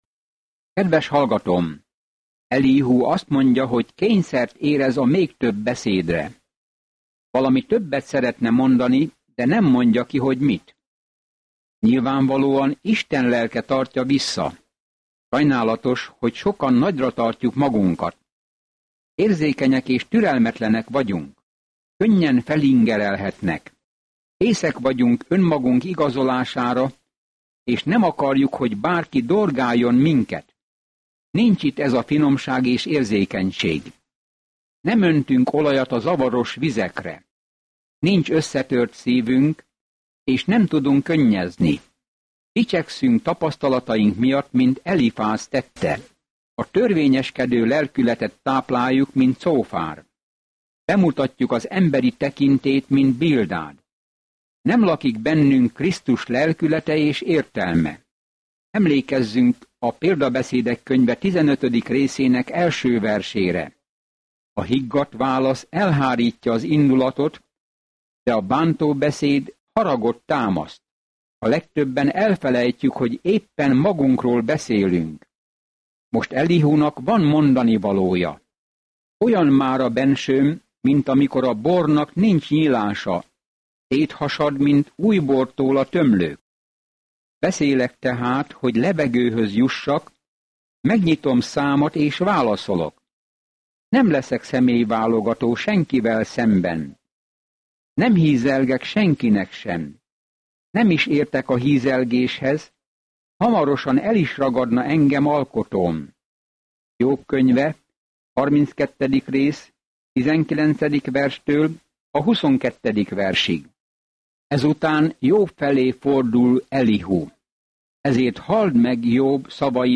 Naponta utazz Jóbon, miközben hallgatod a hangos tanulmányt, és olvasol válogatott verseket Isten szavából.